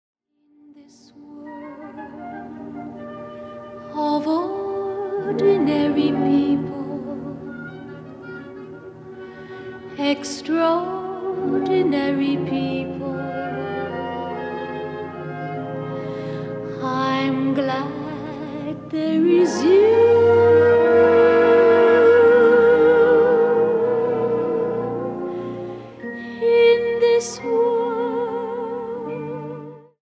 lush and imaginative arrangements for orchestra